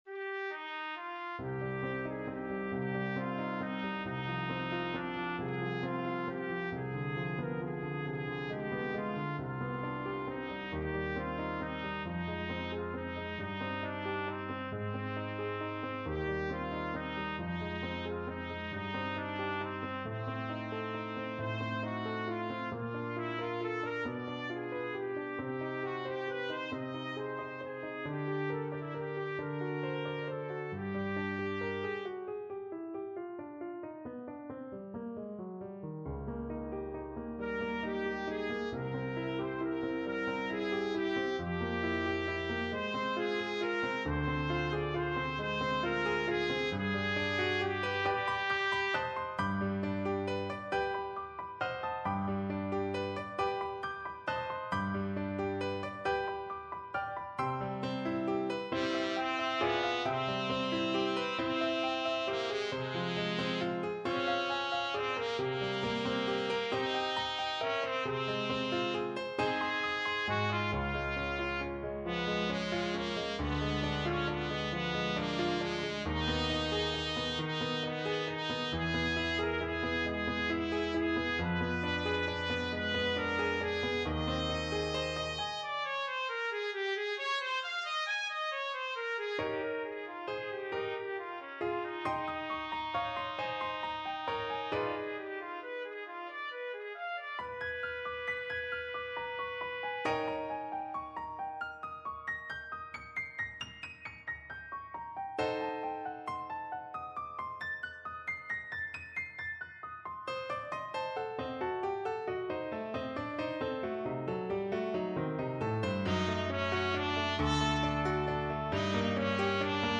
Play (or use space bar on your keyboard) Pause Music Playalong - Piano Accompaniment Playalong Band Accompaniment not yet available transpose reset tempo print settings full screen
Trumpet
6/8 (View more 6/8 Music)
C minor (Sounding Pitch) D minor (Trumpet in Bb) (View more C minor Music for Trumpet )
Andantino .=45 (View more music marked Andantino)
Classical (View more Classical Trumpet Music)